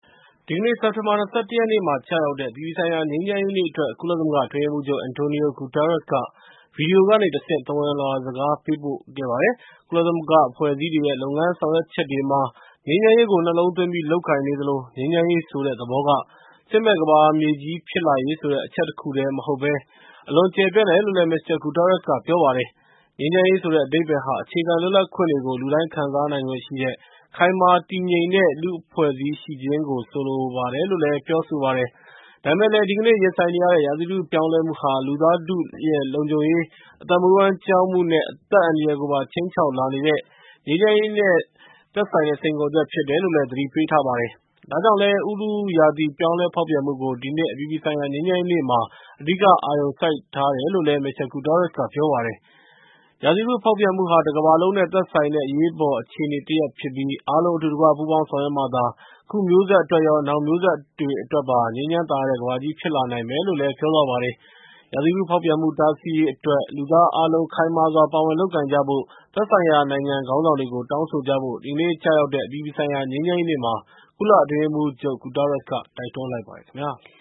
ငြိမ်းချမ်းရေးနေ့ ကုလအတွင်းရေးမှူးချုပ် မိန့်ခွန်း